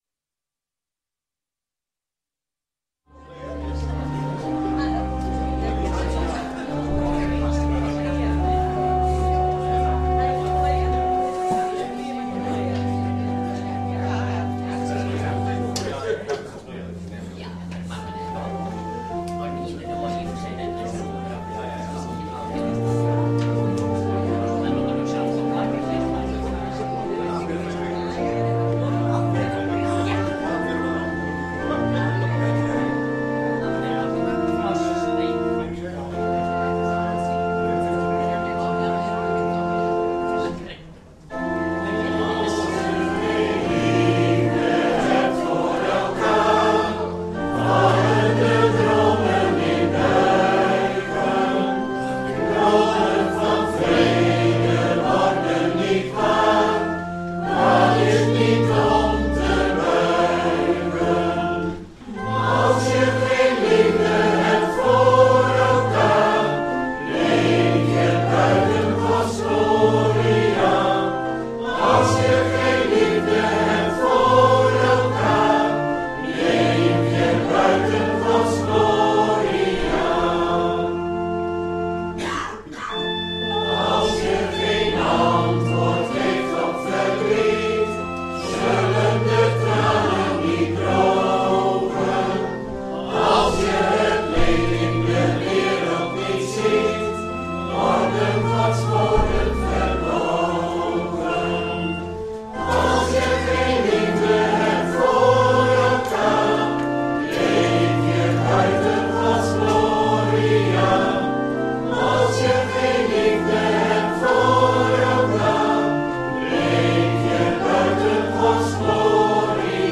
Label: Schriftlezing